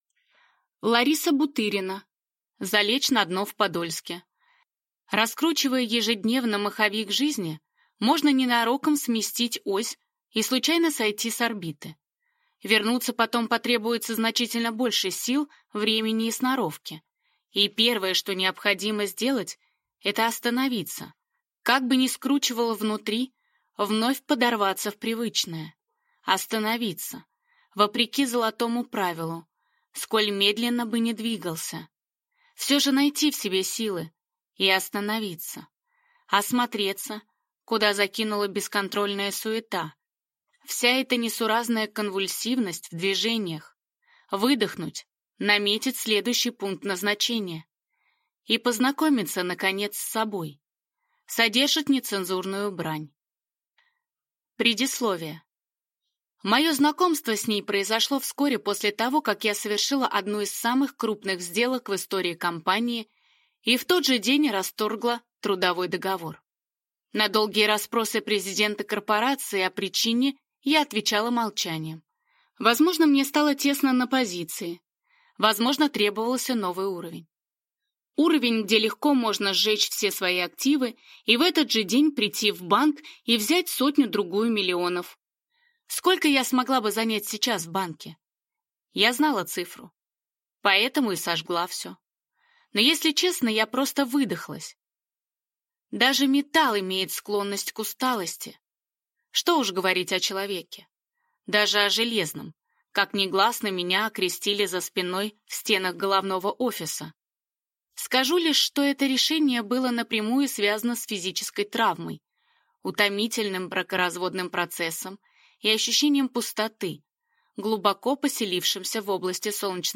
Аудиокнига Залечь на дно в Подольске | Библиотека аудиокниг